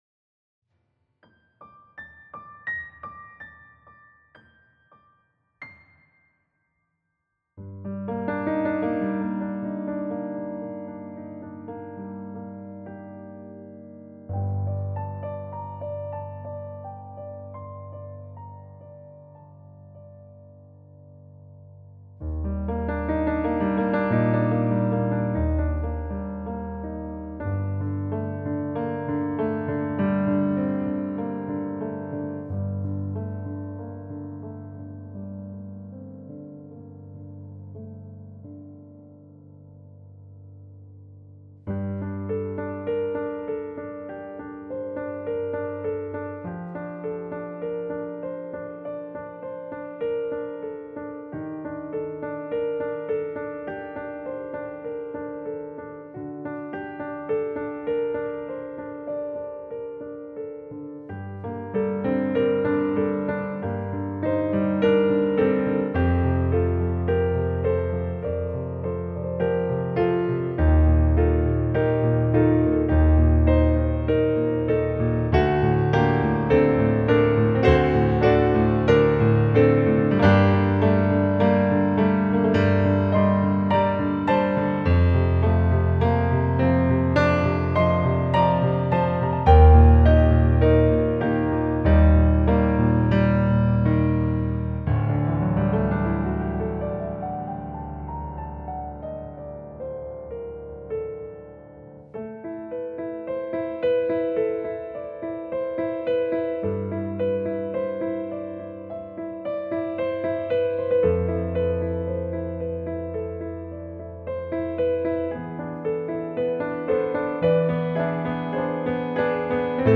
Piano (Intermediate Edition)